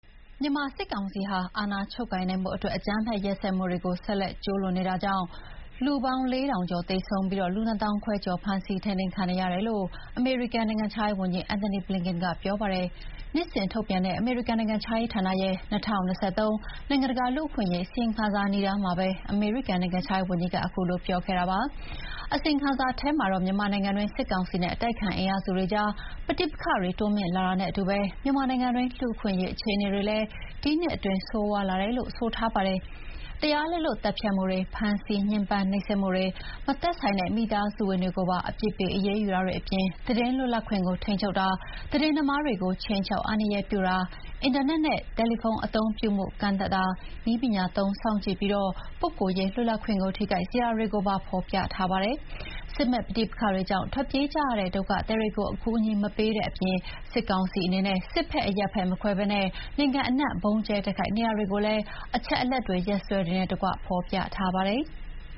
Secretary of State Antony Blinken speaks about the recently released 2023 Country Reports on Human Rights Practices during a briefing at the State Department in Washington, April 22, 2024.